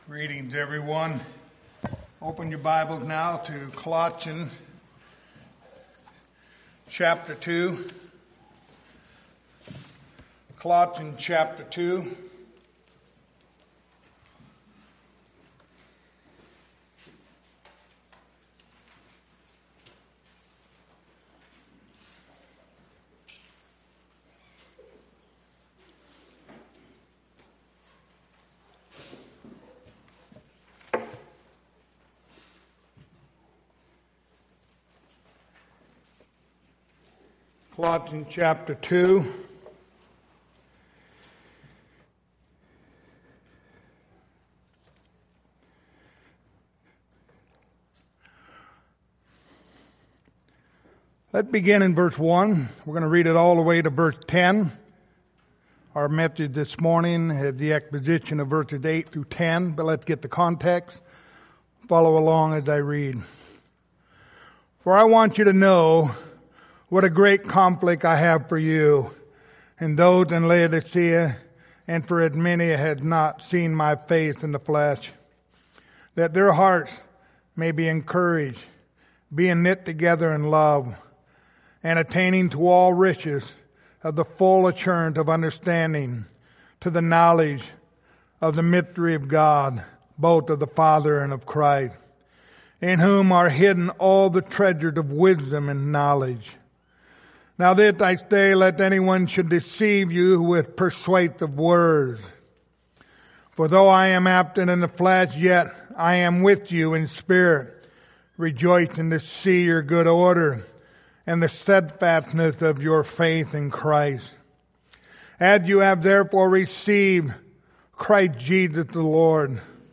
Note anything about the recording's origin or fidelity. The Book of Colossians Passage: Colossians 2:8-10 Service Type: Sunday Morning Download Files Notes Topics